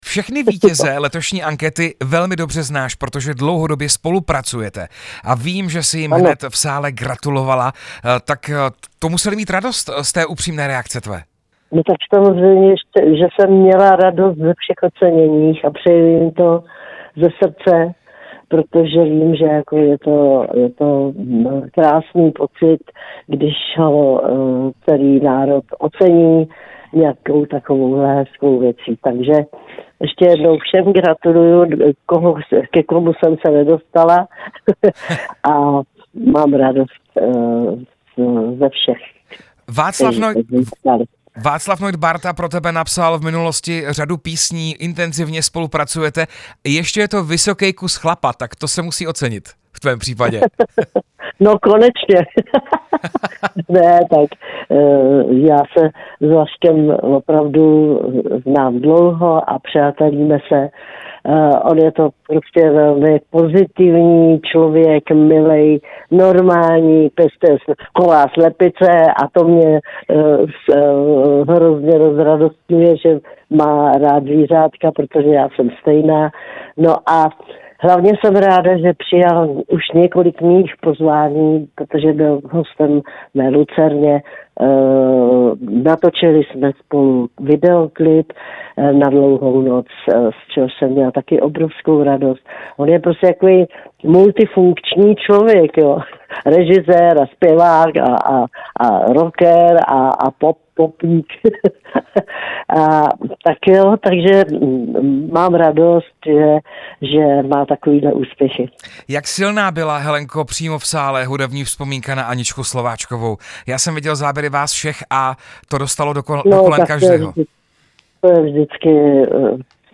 Rozhovor se zpěvačkou Helenou Vondráčkovou